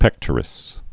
(pĕktər-ĭs)